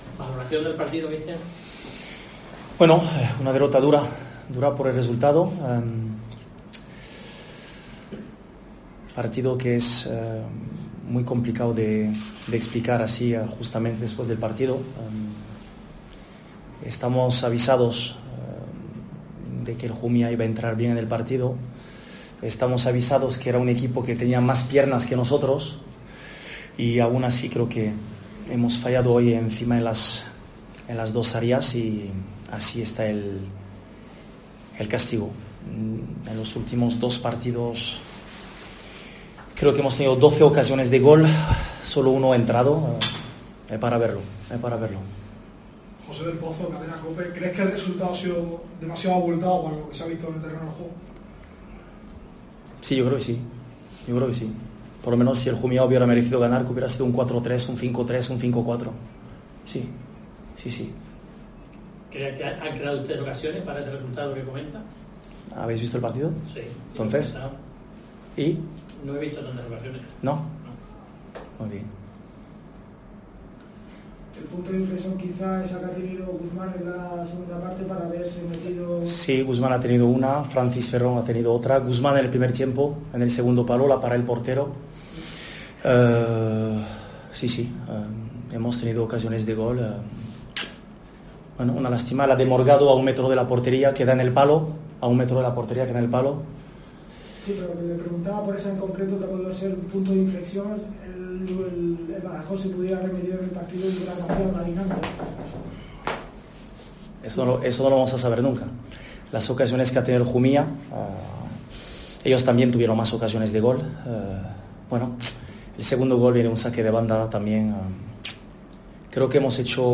Rueda de prensa Jumilla